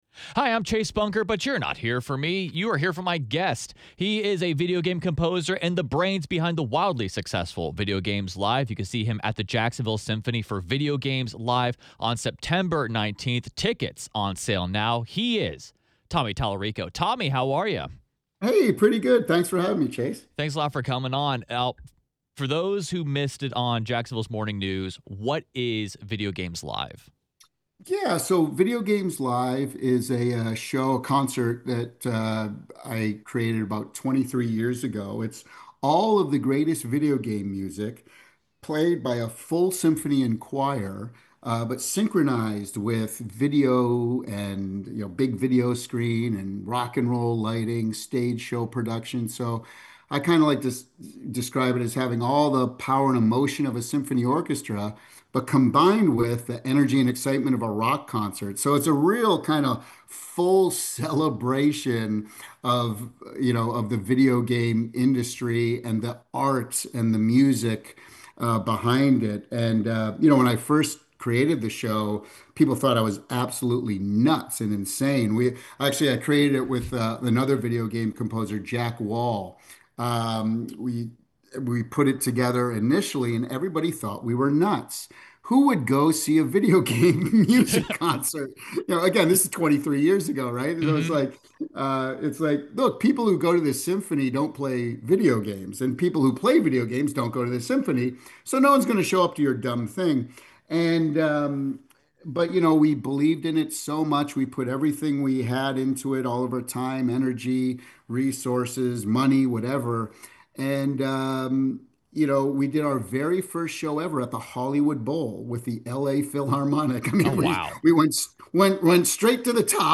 ▶ Listen to Jacksonville’s Morning News Interviews